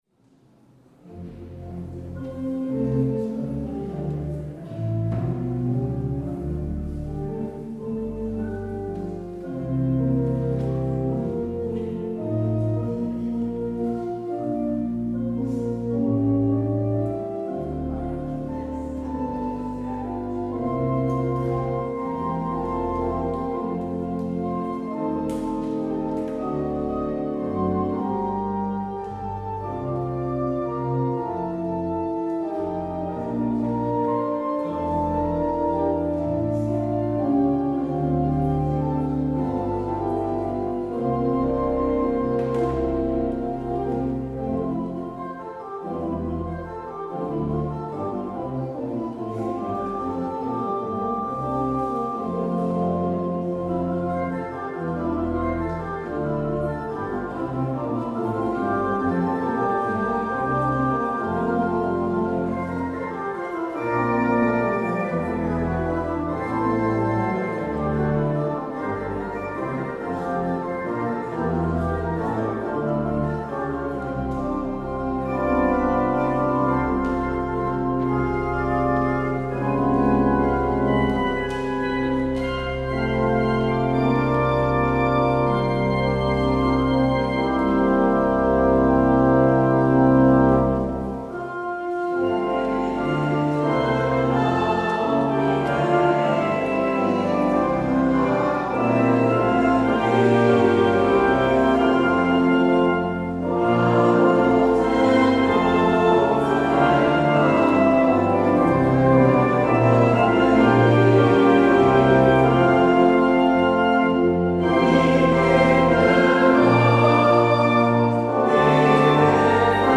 Aan het begin van de zevende maand, toen de Israëlieten zich in hun steden hadden gevestigd, verzamelde het voltallige volk zich op het plein voor de Waterpoort Het openingslied is: Psalm 19: 3 en 4.
Het slotlied is: Gezang 305: 2 (LvdK).